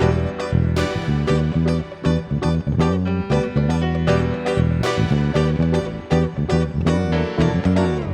12 Backing PT2.wav